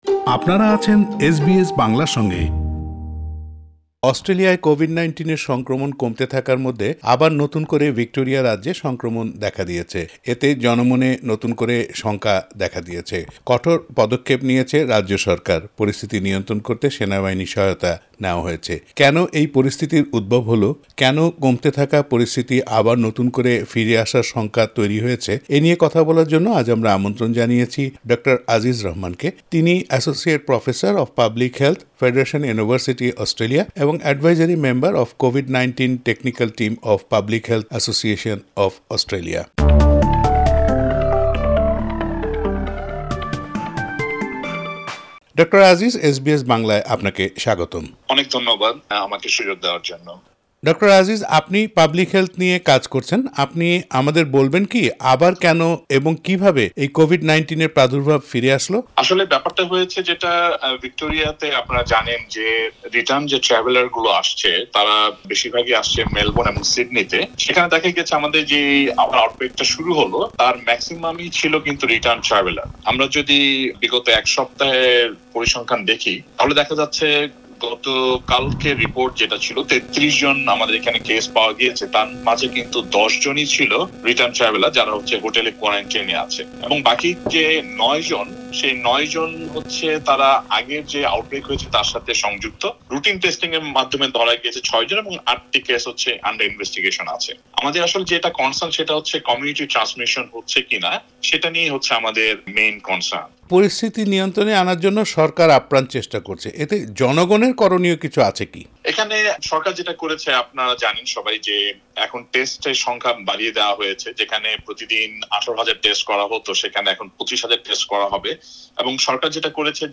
এস বি এস বাংলার সাথে কথা বলেছেন